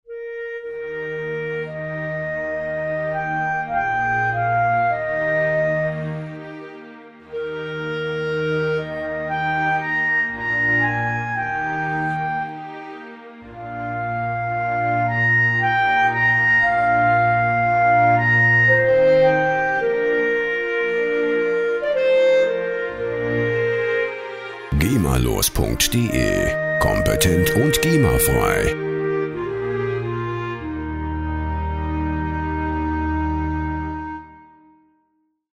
Instrument: Klarinette
Tempo: 60 bpm